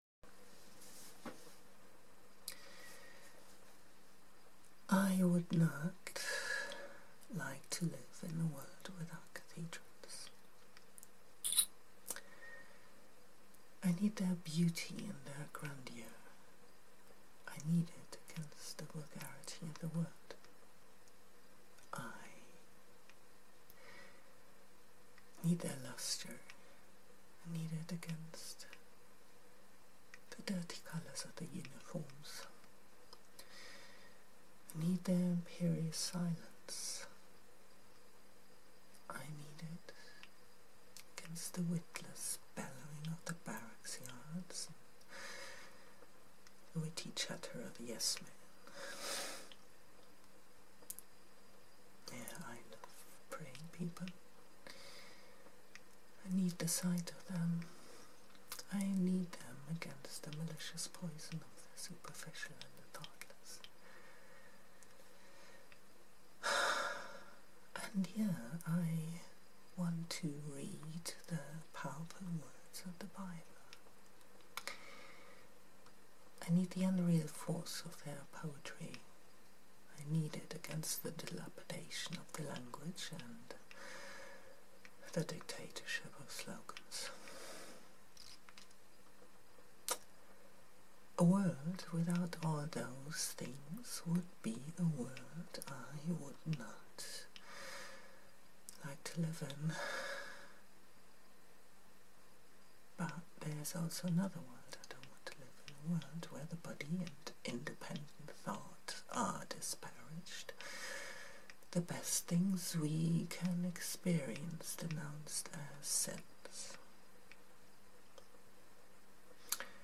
Graduation Speech Amadeu de Prado from "Nighttrain to Lisbon".
Not exactly sure if me speaking them, gives them the desired impact...but I so wanted to say them.